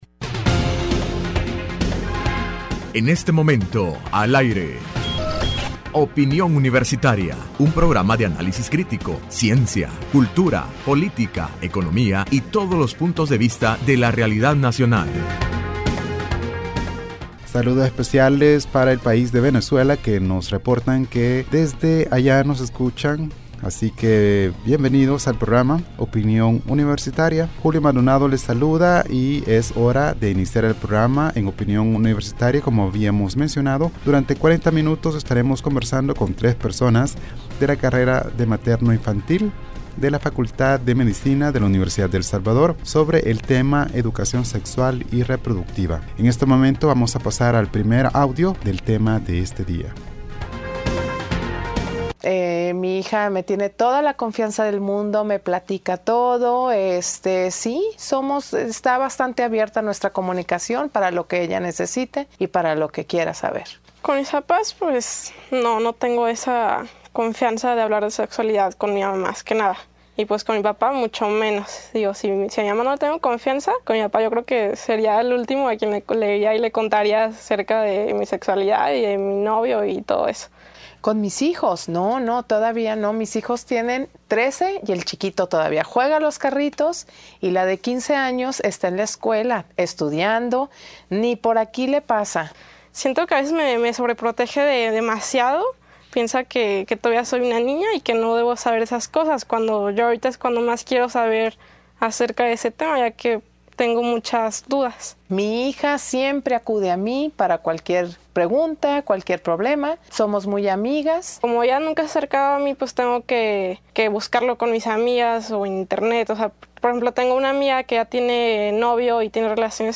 Entrevista Opinión Universitaria (16 mayo 2016) :Educación sexual y reproductiva